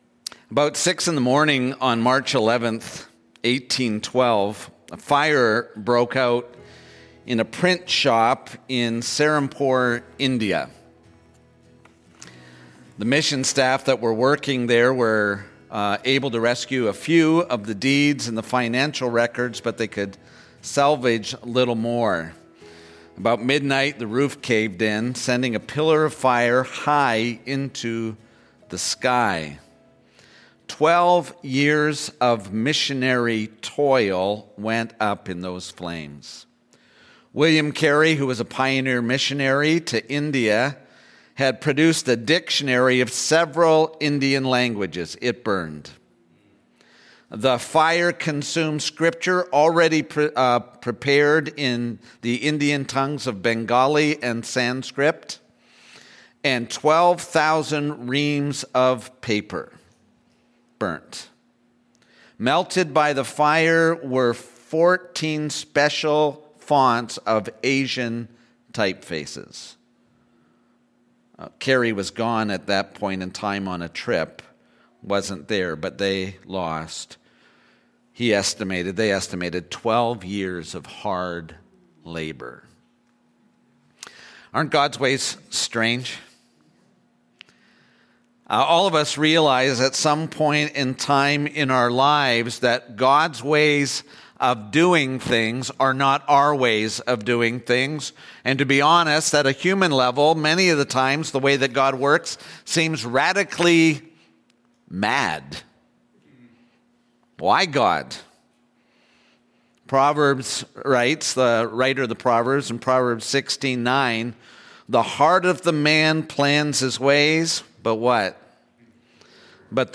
Sermons | Waterbrooke Christian Church